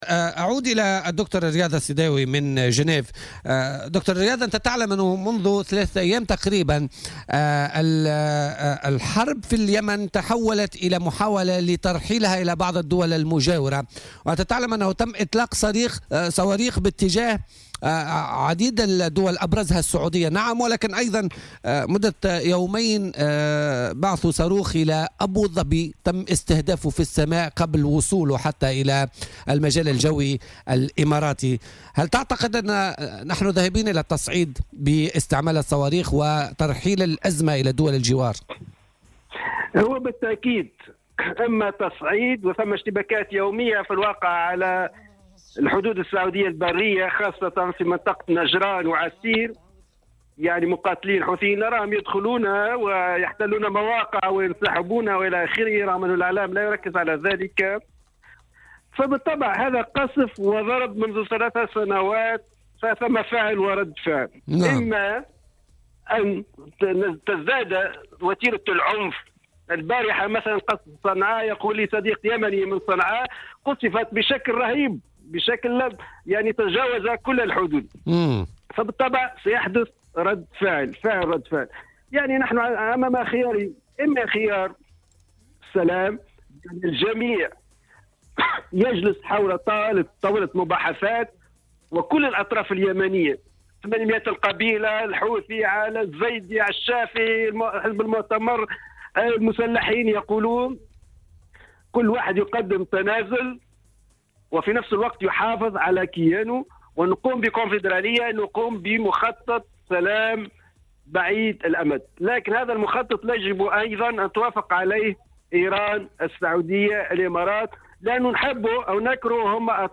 مداخلته في برنامج "بوليتيكا"